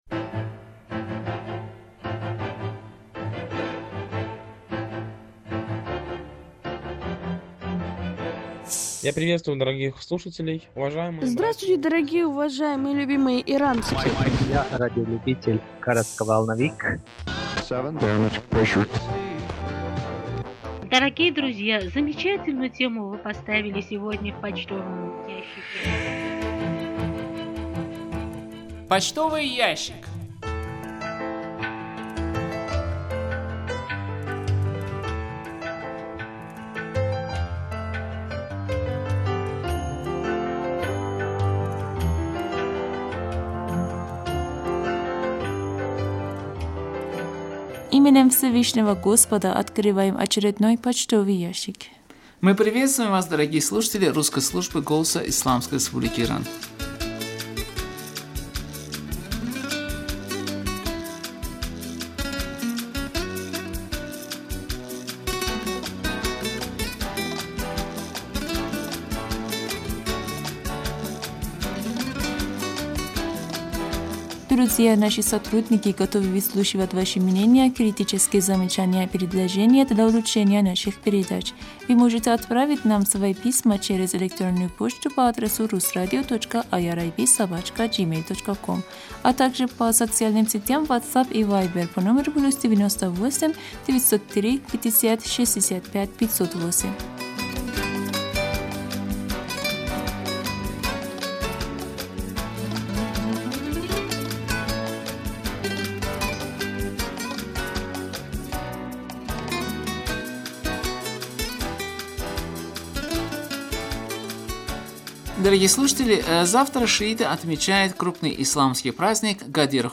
Беседа